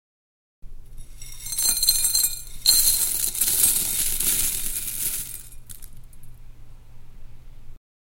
Высыпаем чечевицу аккуратно